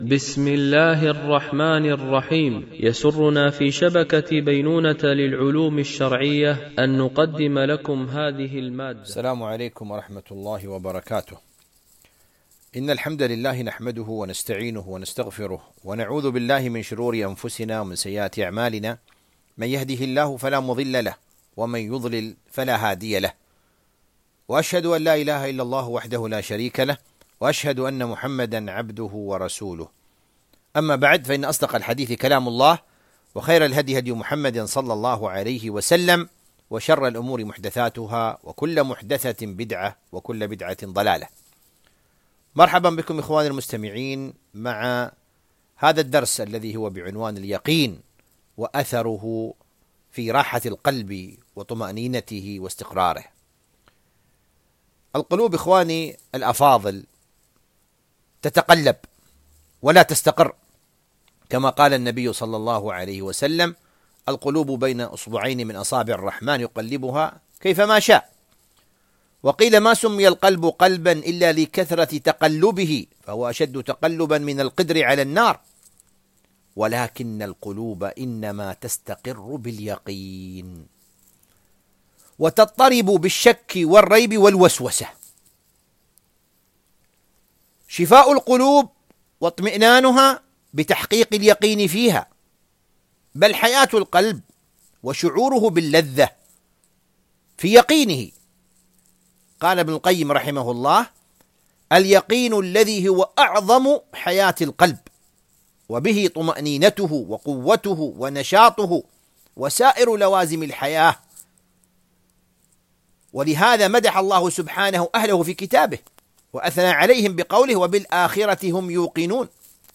التنسيق: MP3 Mono 44kHz 96Kbps (VBR)